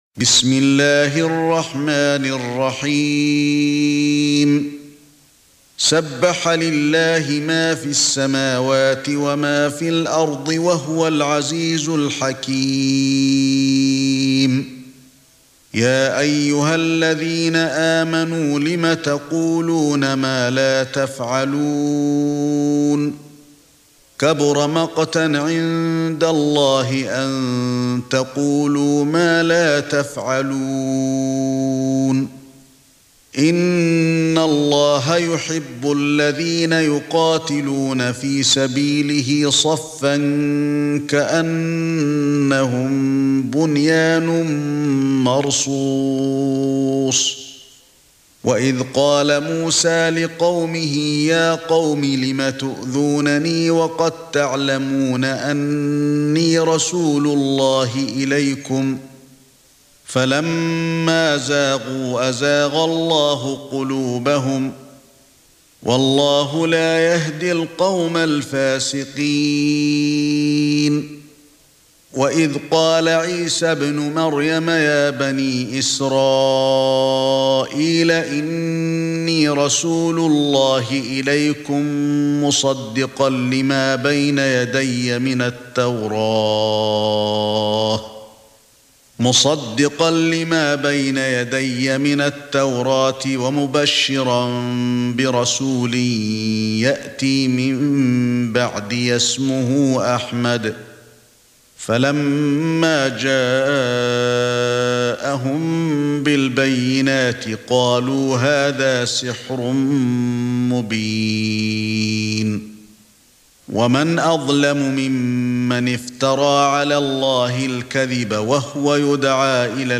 سورة الصف ( برواية قالون ) > مصحف الشيخ علي الحذيفي ( رواية قالون ) > المصحف - تلاوات الحرمين